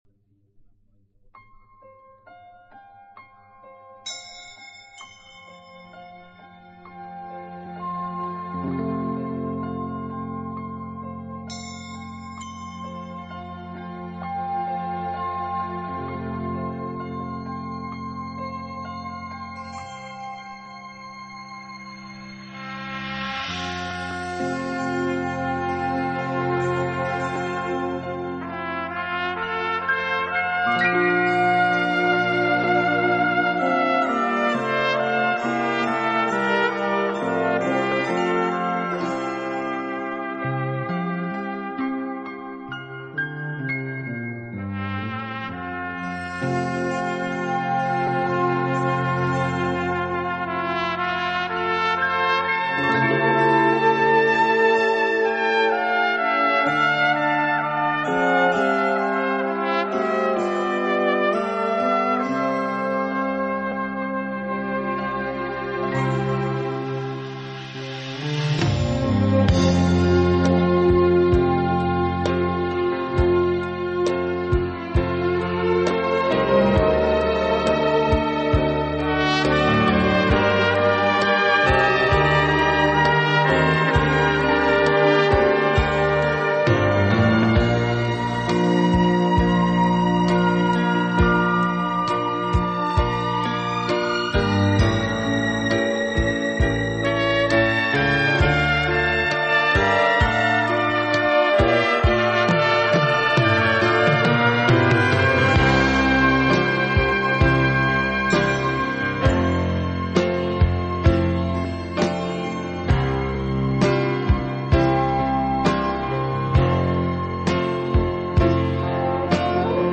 Tonträger:LP
Musikrichtung:Pop